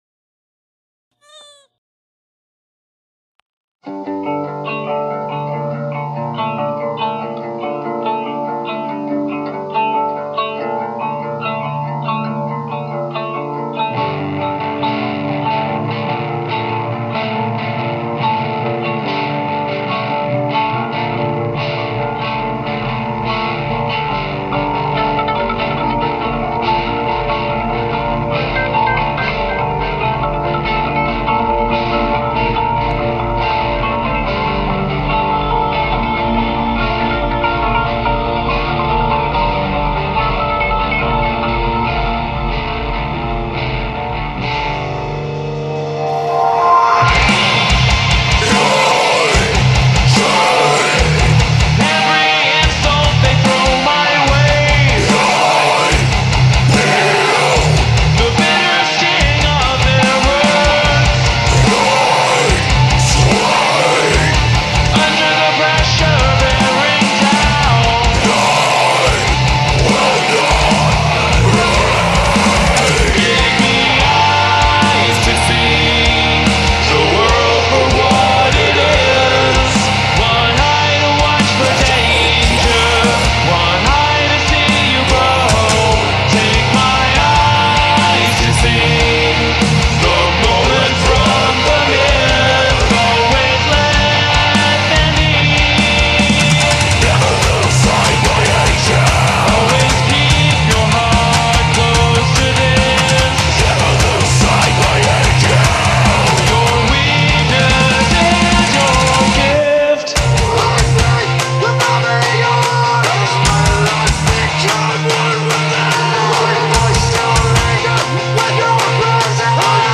lead vocals both clean and unclean, bass guitar
additional vocals, claps